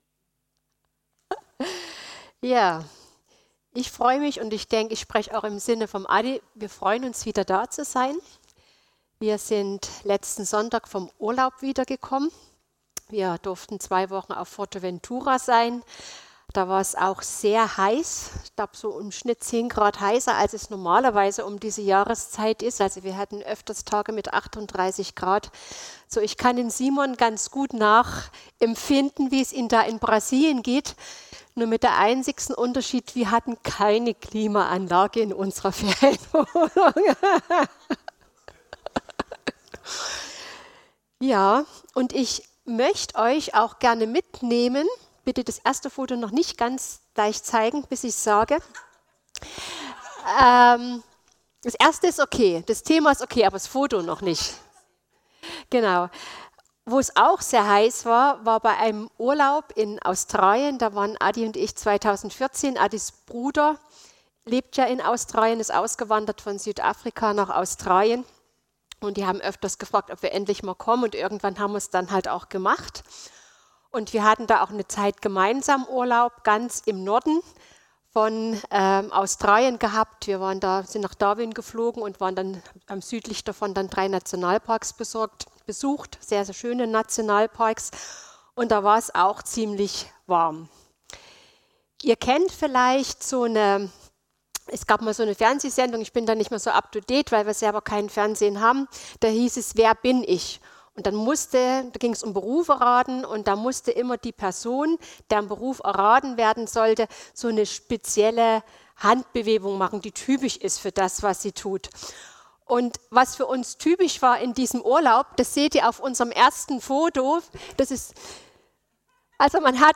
2023 …vom Richten Prediger